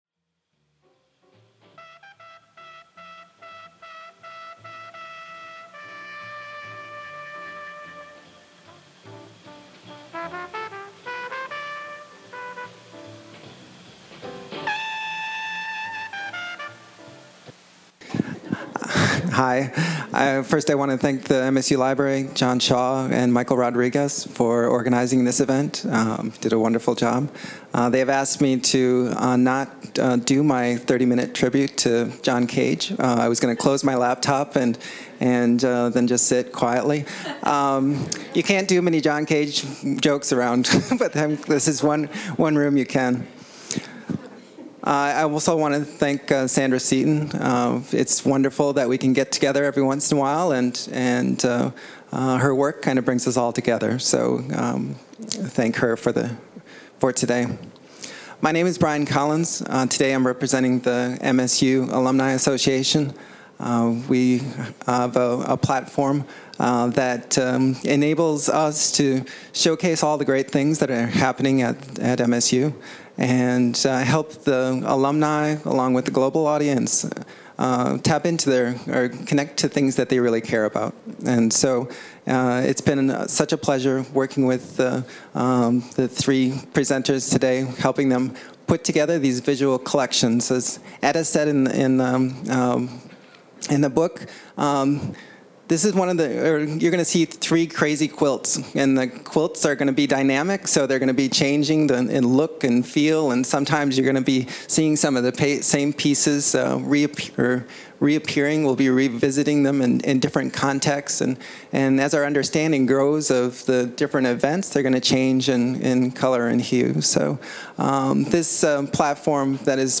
Held at the MSU Main Library.